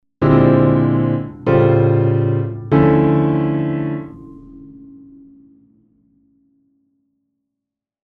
Drei komplexe Latin Jazz Akkorde ausnotiert: B♭(#11)13, A(#9)♭13, Dm(maj7)9
latinjazz_am_klaver_3chords.mp3